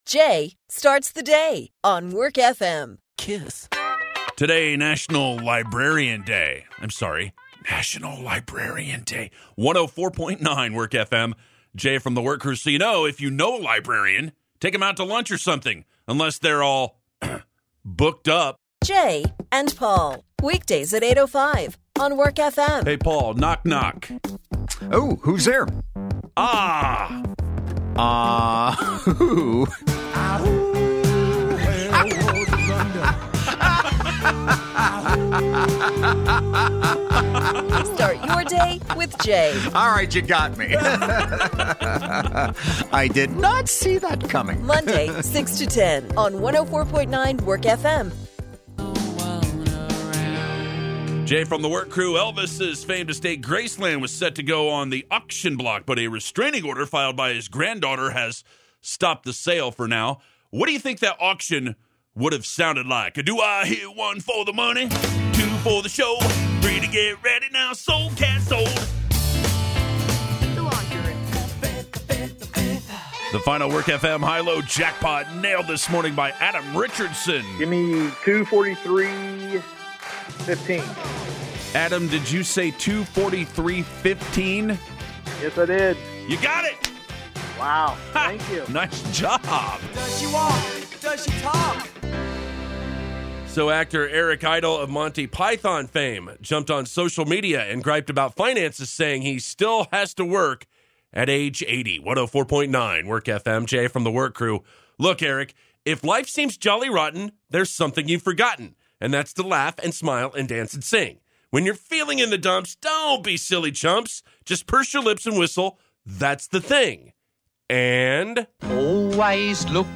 Audio samples of on-air work from an individual or a group of people for news, entertainment, or sports – but not sports play-by-play.